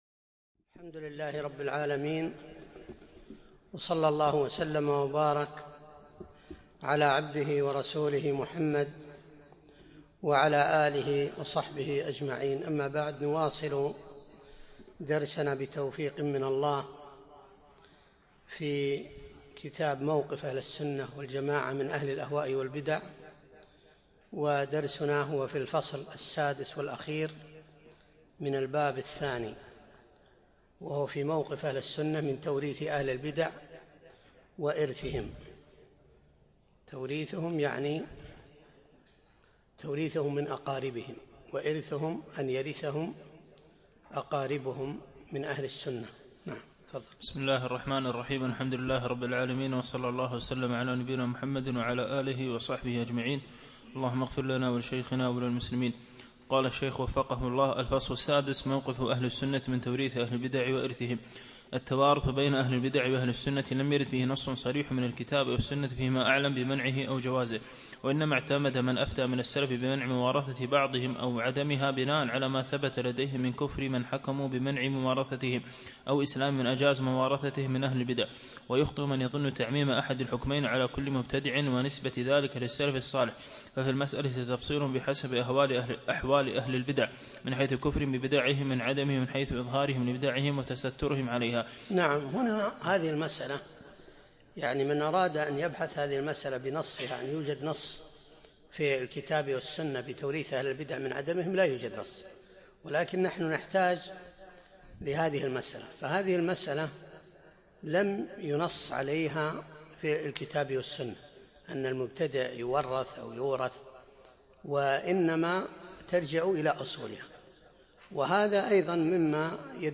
بعد العصر يوم الخميس 23 جمادى الأول 1437هـ الموافق 3 3 2016م في مسجد كليب مضحي العارضية